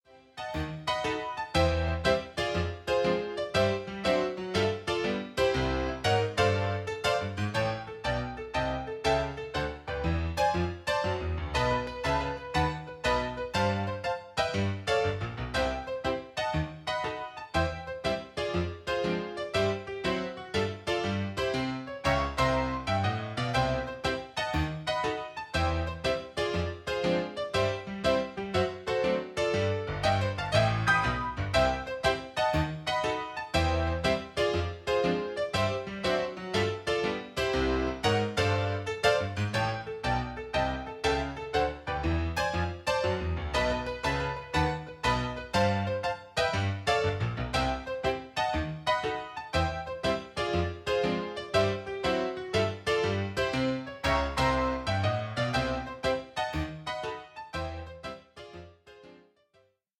Das Playback-Album zur gleichnamigen Produktion.
Playback ohne Backings, gleiche Reihe 2,99 €